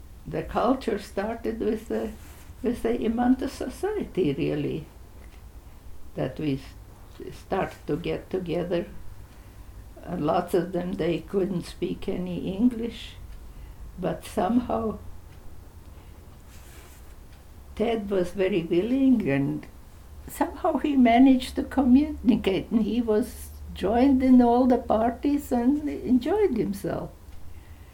Viola on Imanta